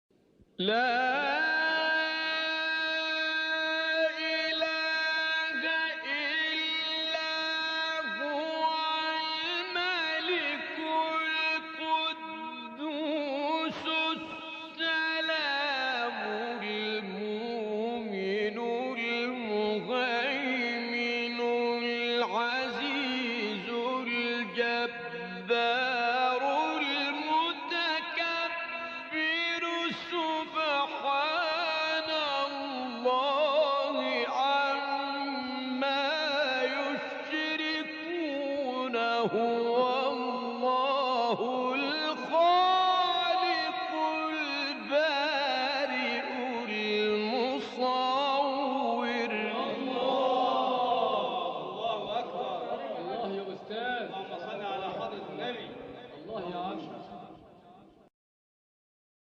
گروه شبکه اجتماعی: مقاطعی از قاریان مصری که در مقام رست اجرا شده است، می‌شنوید.
مقام رست